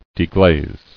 [de·glaze]